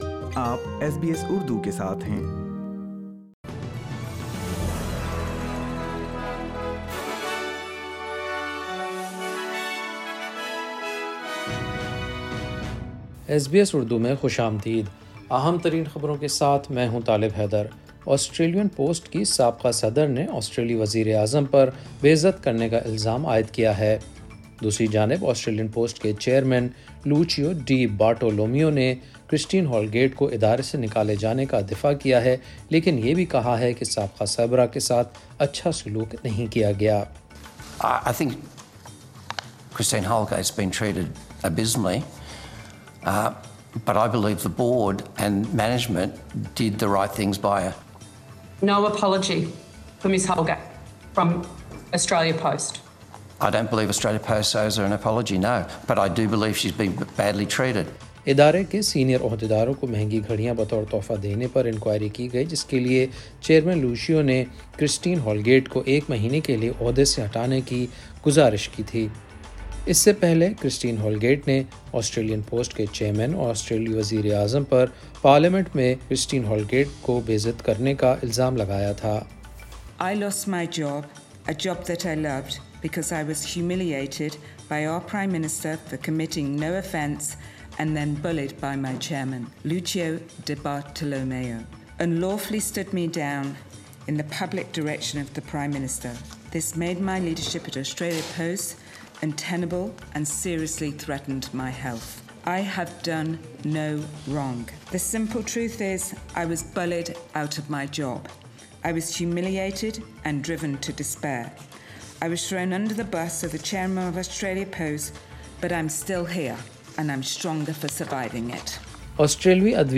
In this bulletin, a former Australia Post chief accuses the Prime Minister of bullying and humiliating her without justification.. Health authorities move to reassure the public about COVID-19 vaccine safety after a second blood clot case is linked to the AstraZenica dose...